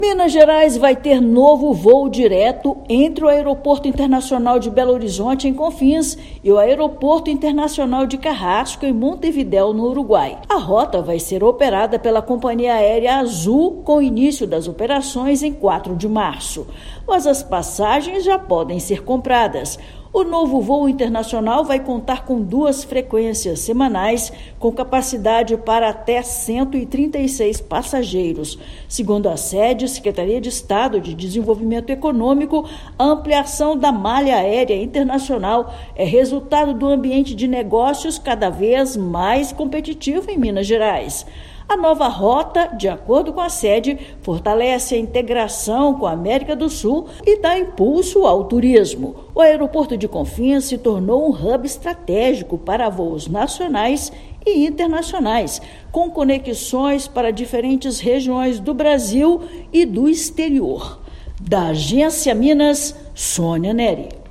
[RÁDIO] Governo de Minas celebra novo voo internacional que liga Confins a Montevidéu
Nova rota estará disponível a partir de 4/3, com duas frequências semanais, às quartas-feiras e aos domingos. Ouça matéria de rádio.